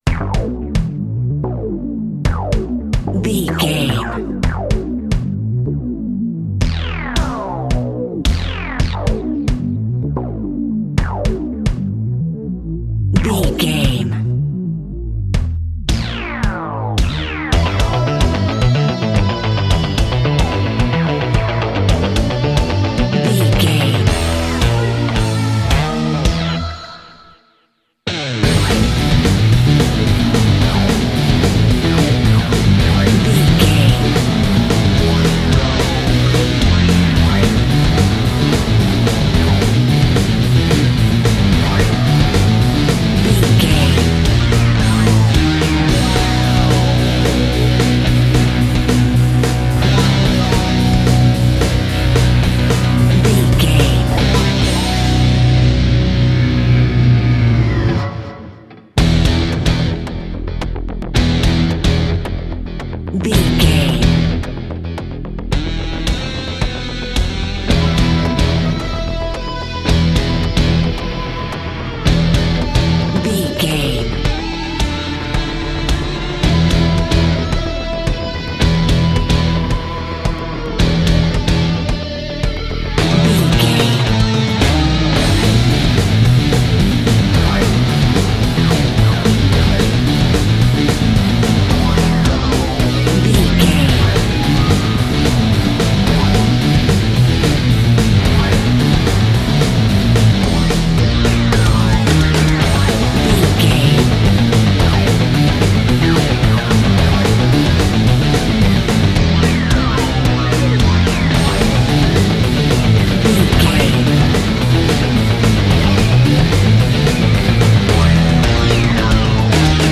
Epic / Action
Fast paced
Aeolian/Minor
Fast
metal
rock